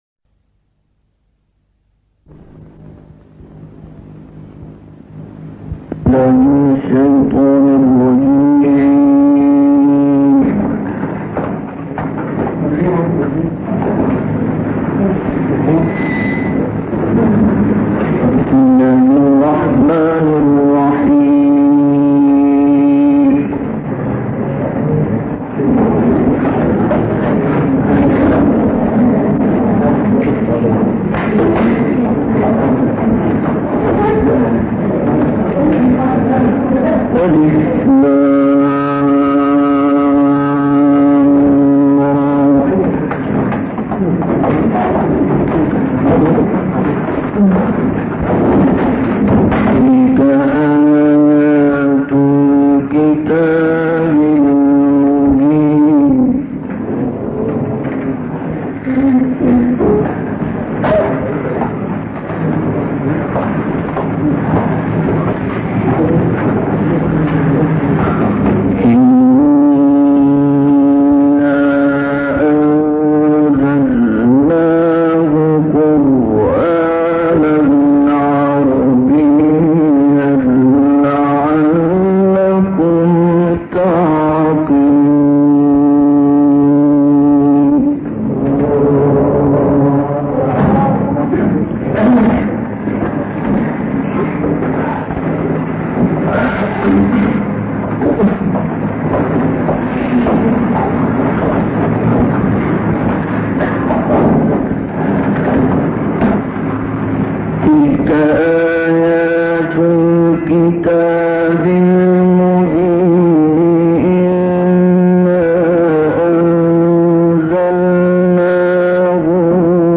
تلاوت «عبدالباسط» در حلب
این تلاوت در سال 1957 میلادی اجرا شده است.